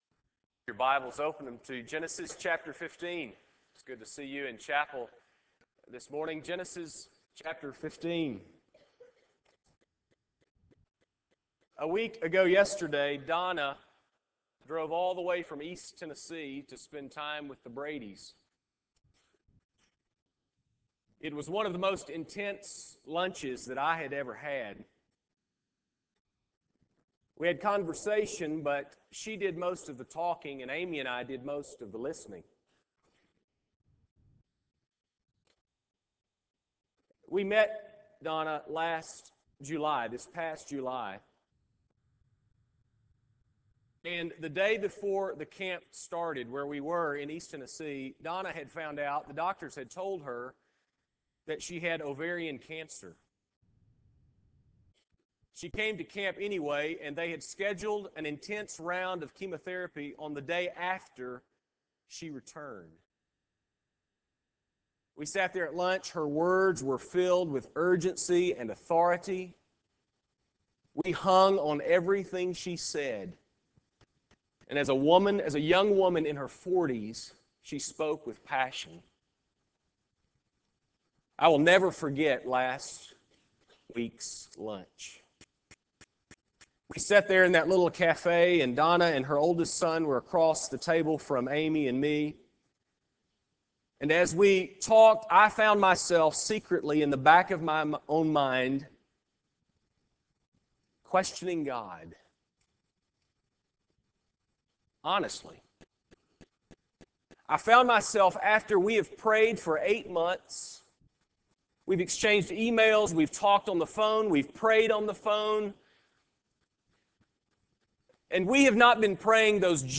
Chapel Service